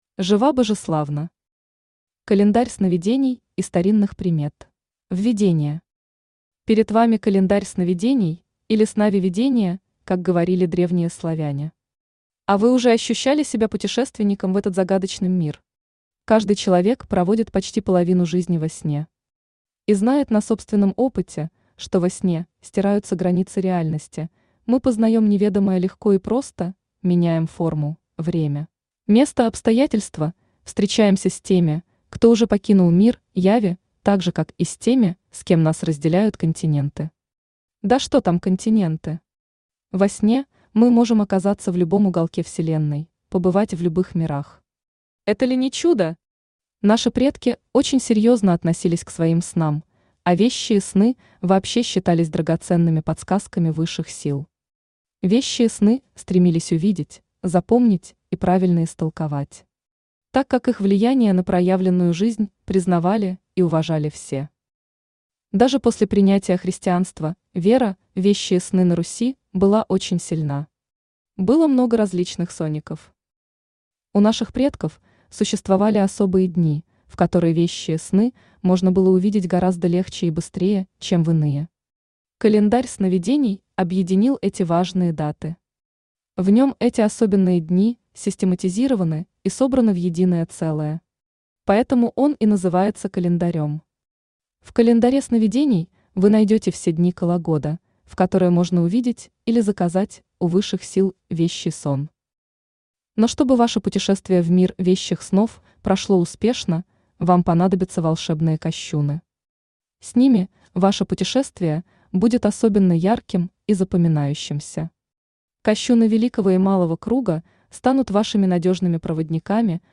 Aудиокнига Календарь сновидений и старинных примет Автор Жива Божеславна Читает аудиокнигу Авточтец ЛитРес.